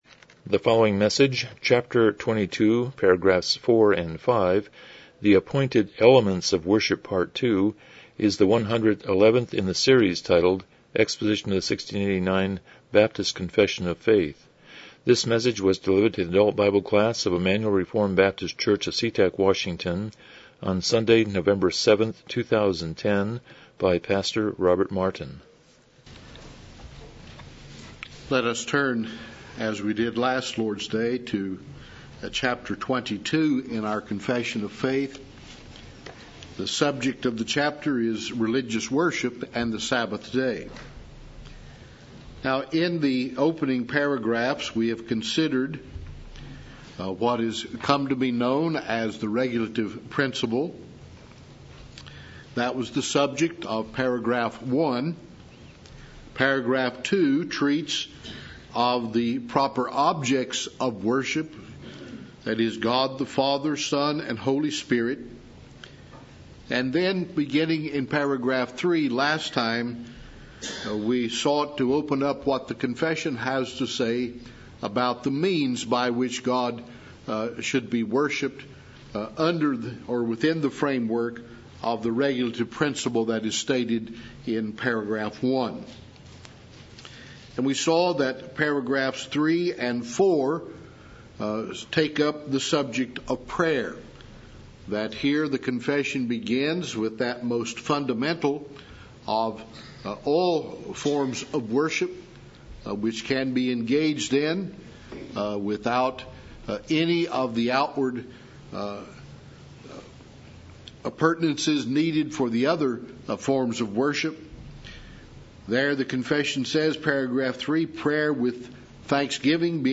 1689 Confession of Faith Service Type: Sunday School « 14 Philippians 2:12-13